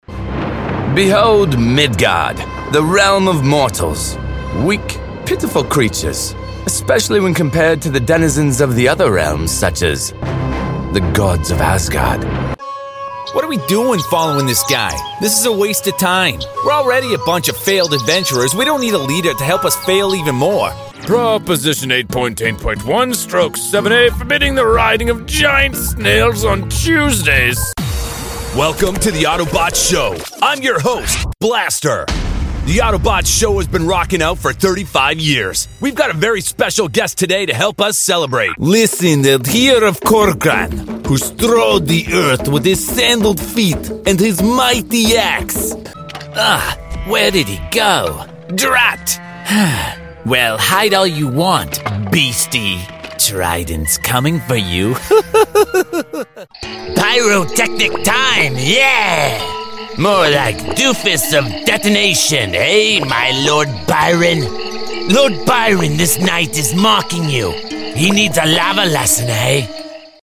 Animation Demo
Standard American, New York, California, Canadian West Coast.
Cool, edgy millennial (with just enough sarcasm)